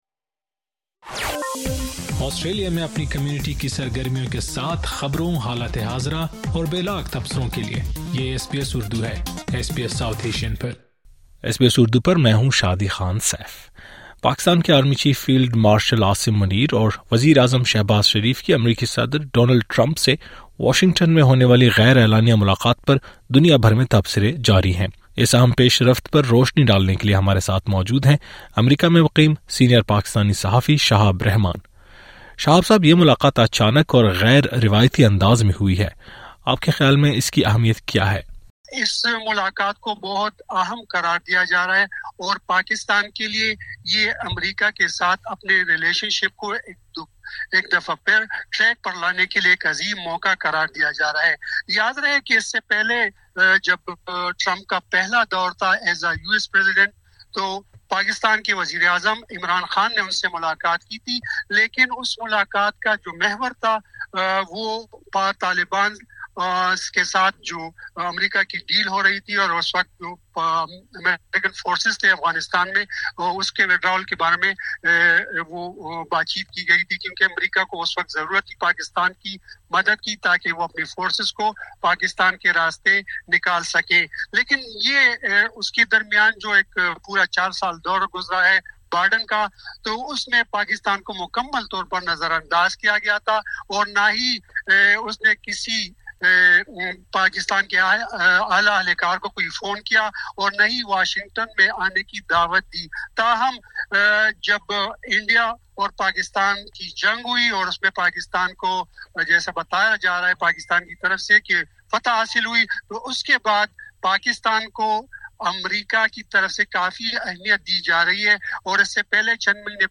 The unannounced meeting between Pakistan Army Chief Field Marshal Asim Munir and Prime Minister Shehbaz Sharif with US President Donald Trump is generating worldwide attention. Senior US-based Pakistani journalist